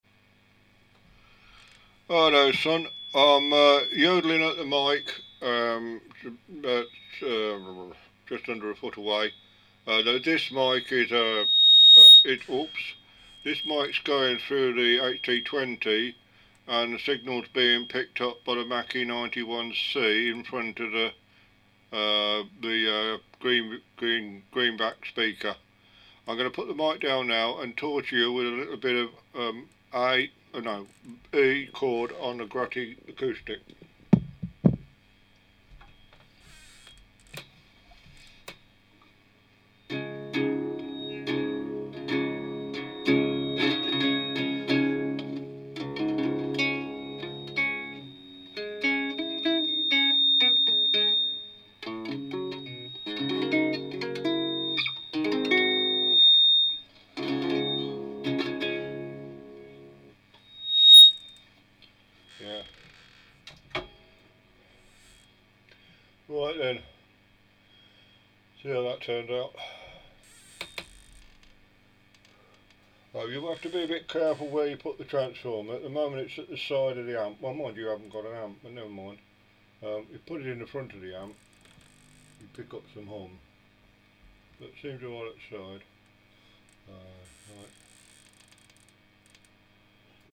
Mackie 91C LDC in front of Greenback cab fed from HT-20 amp.
Prodipe T11 dynamic mic on traff XLR.
That feeds back VERY suddenly. 91c into NI KA6 and recorded in Samplitude. I have attached the whole thing, warts, fumbles and ***t playing and all.